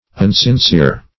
Search Result for " unsincere" : The Collaborative International Dictionary of English v.0.48: Unsincere \Un`sin*cere"\, a. Not sincere or pure; insincere.